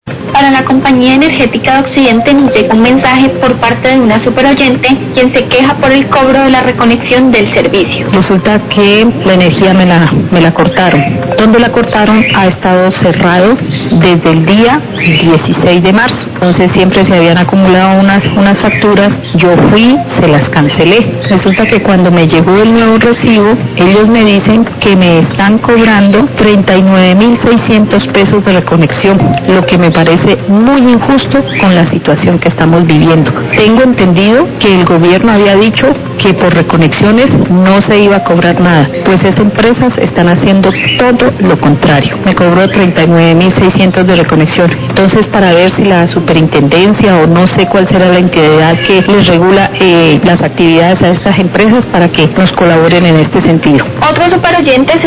Queja de oyente por reconexión en el servicio de energía eléctrica
Radio